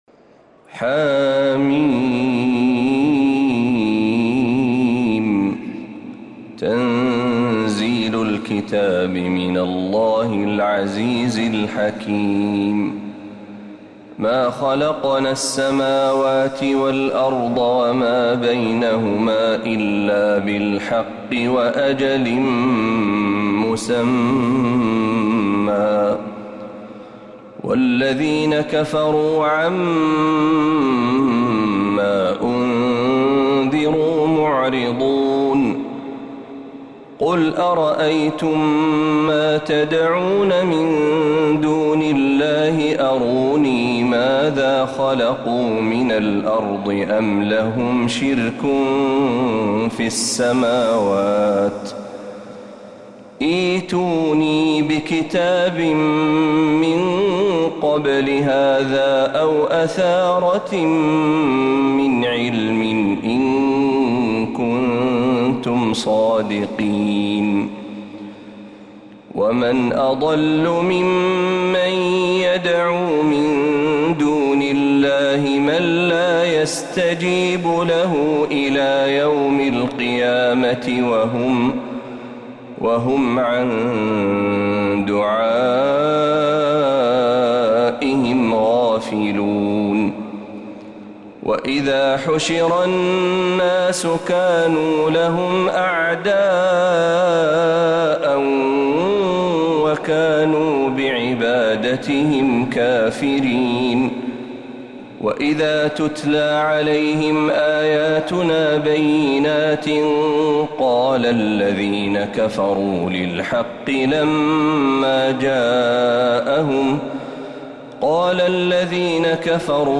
سورة الأحقاف كاملة من الحرم النبوي